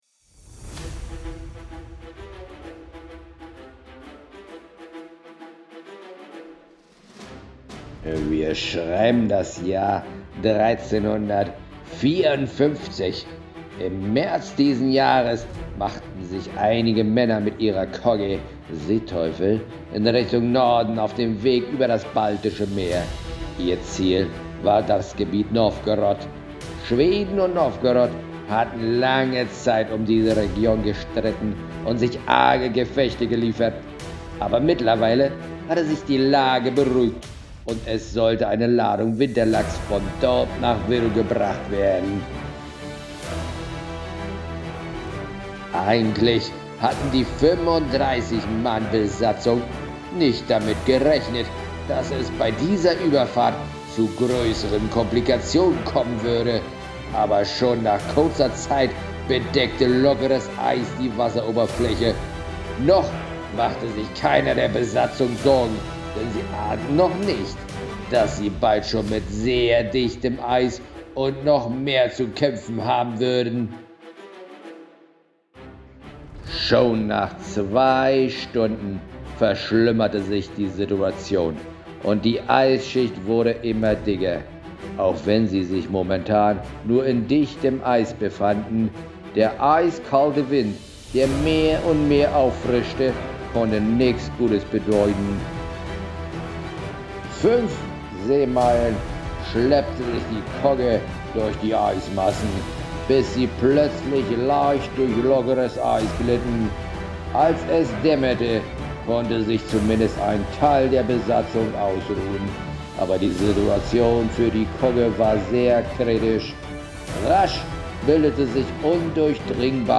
Lauscht den Aufzeichnungen eines alten Kapitäns und findet des Rätsels Lösung.